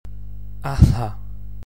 I have pronounced them between two a’s sounds everytime, so it is clearer (sometimes sounds are not that clear at the beginning or at the end of a word).
My dialect is the Northwestern Donegal one.
devoiced broad l.mp3